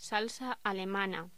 Locución: Salsa alemana
Sonidos: Voz humana